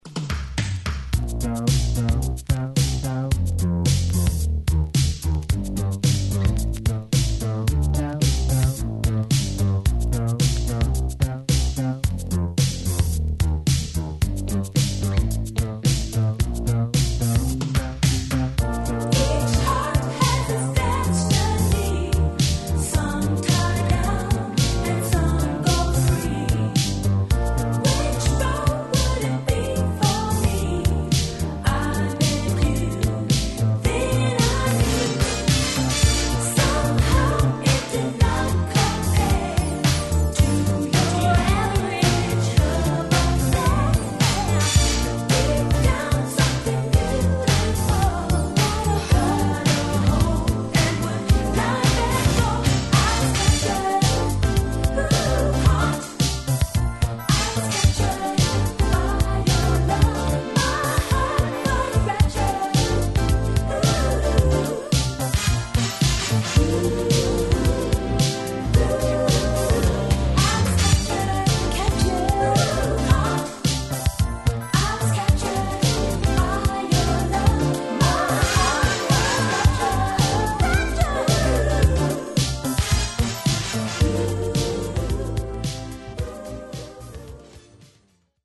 Groovy Disco!!